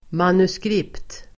Uttal: [manuskr'ip:t]